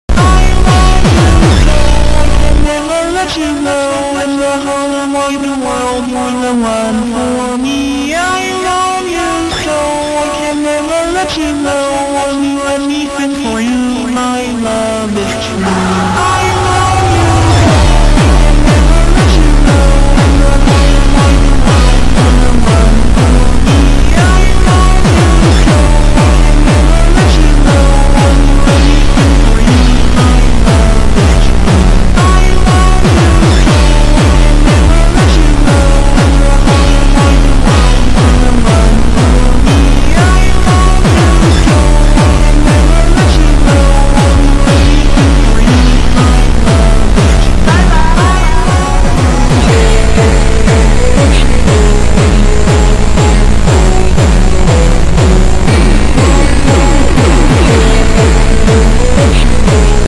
DHL 767 taking off from sound effects free download
DHL 767 taking off from EGNX / EMA